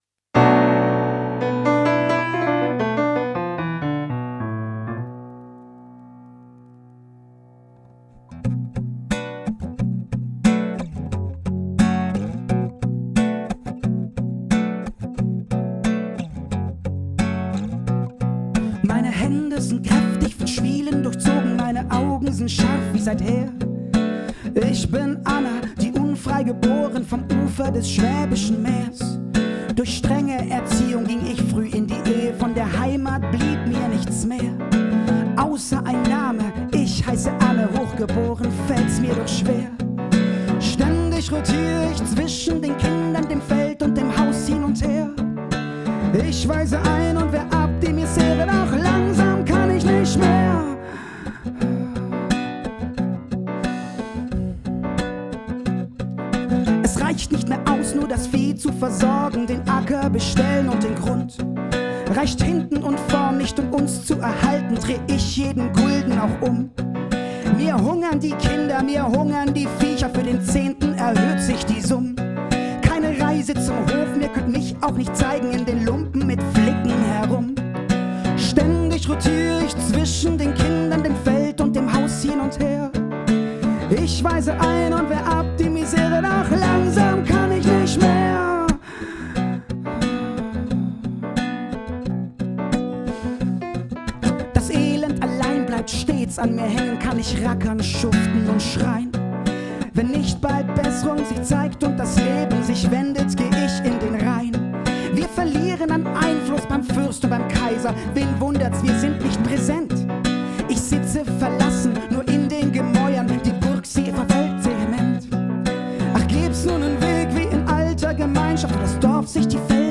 Gesang
Gitarre
Keyboard